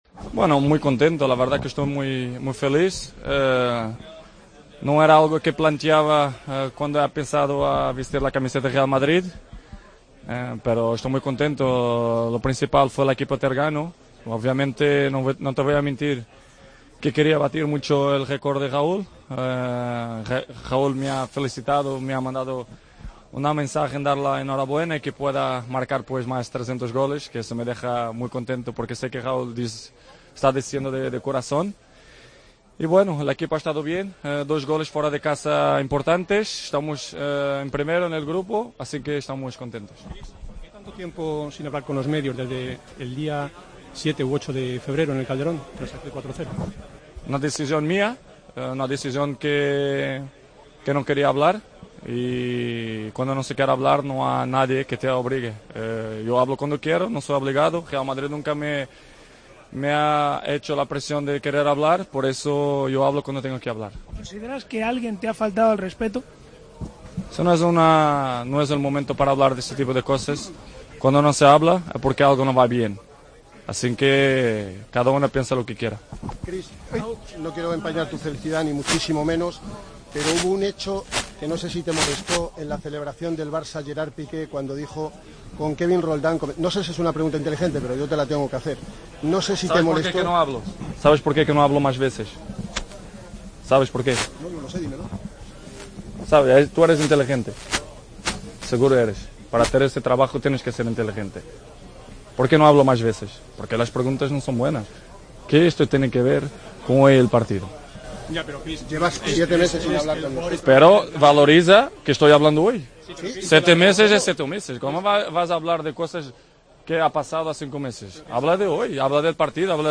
Cristiano Ronaldo atendió a los medios en la zona mixta siete meses después de su última comparecencia.